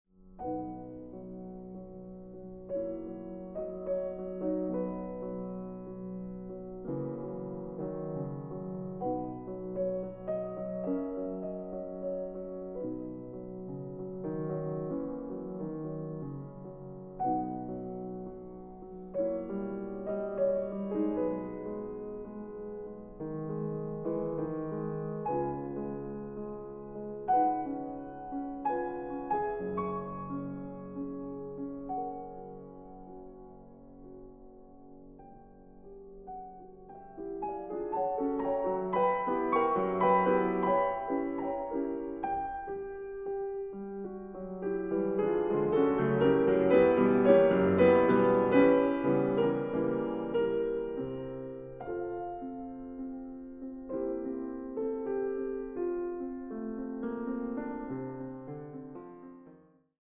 Klavier